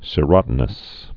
(sĭ-rŏtn-əs, sĕrə-tīnəs)